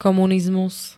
komunizmus [-n-] -mu m.
Zvukové nahrávky niektorých slov